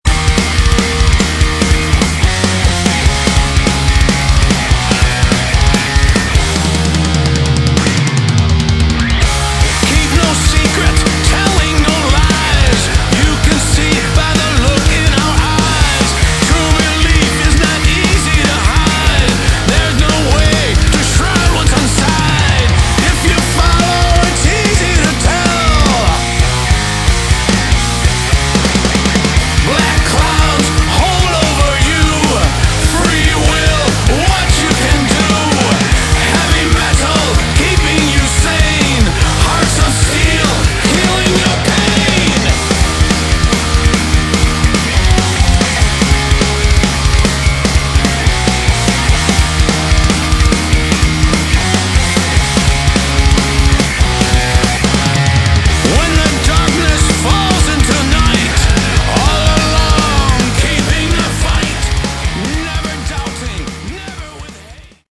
Category: Melodic Metal
vocals, guitar
drums, percussion, vocals
bass, vocals